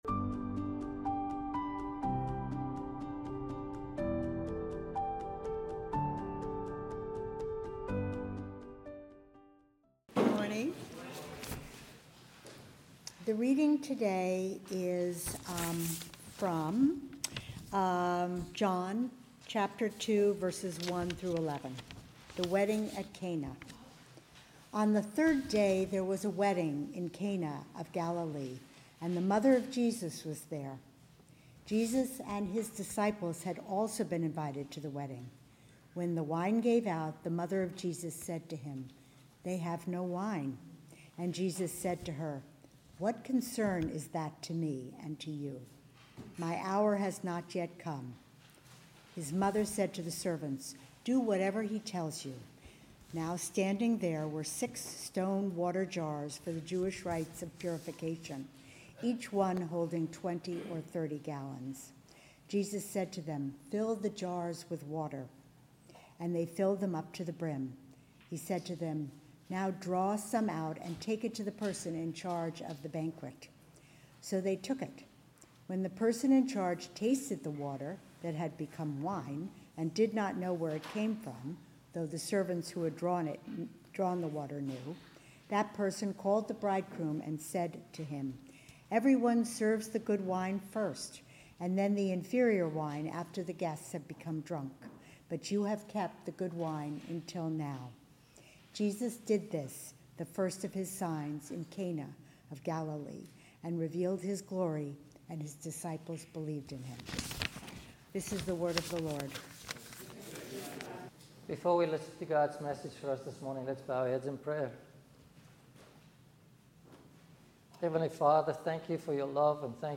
Sermons | Community Church of Douglaston